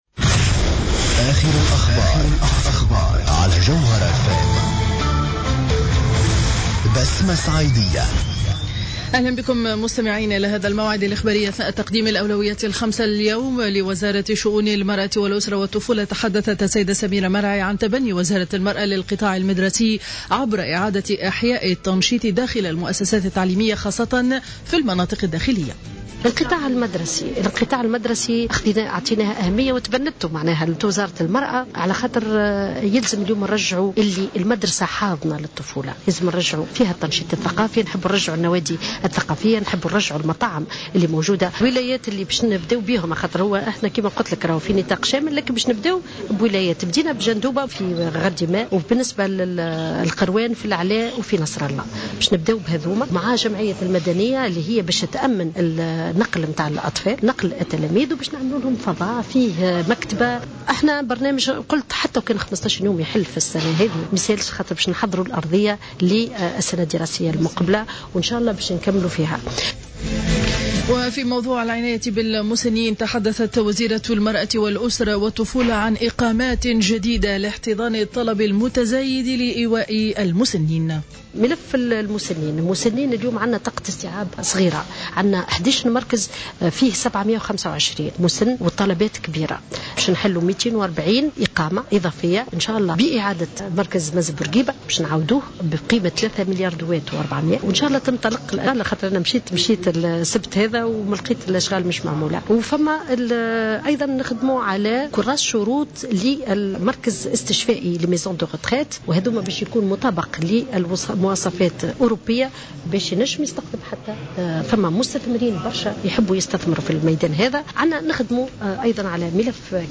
نشرة أخبار منتصف النهار ليوم الخميس 2 أفريل 2015